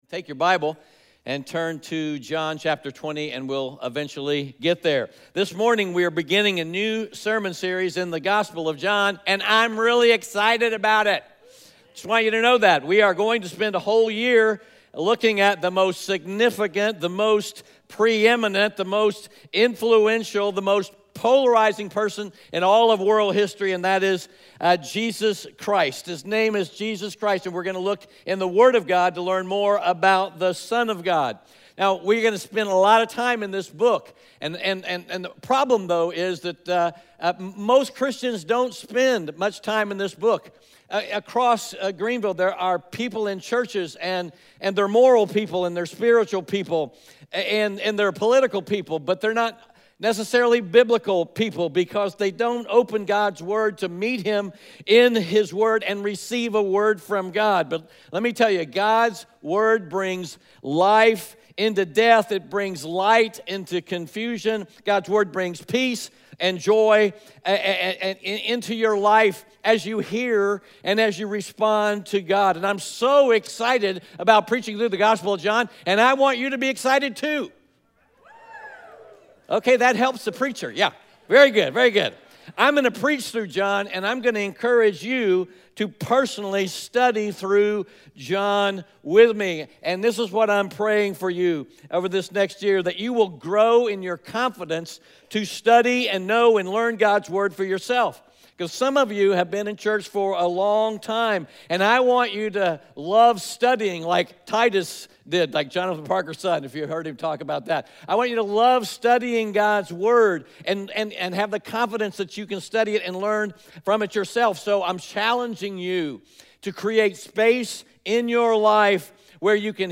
John 20:30-31; John 1:1-3 Audio Sermon Notes (PDF) Ask a Question Scripture: John 20:30-31; John 1:1-3 Today we are beginning a new sermon series in the Gospel of John.